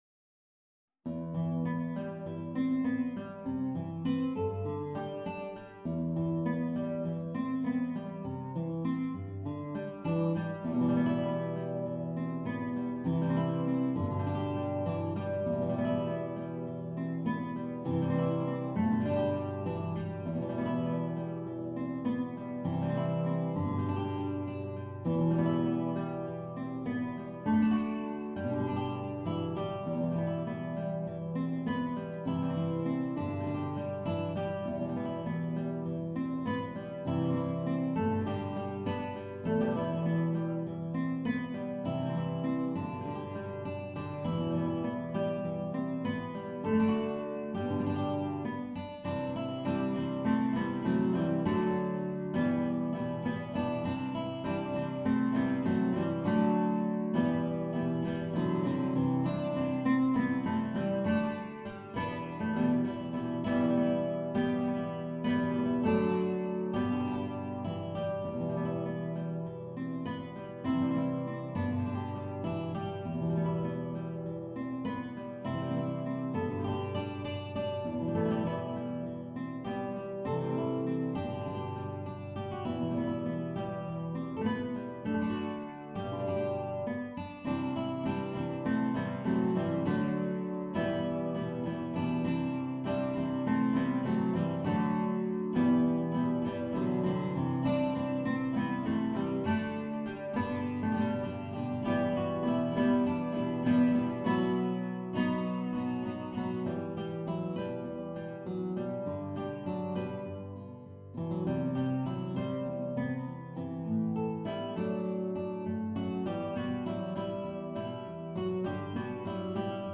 Guitar Quartet – Intermediate Level
MIDI Mockup Recording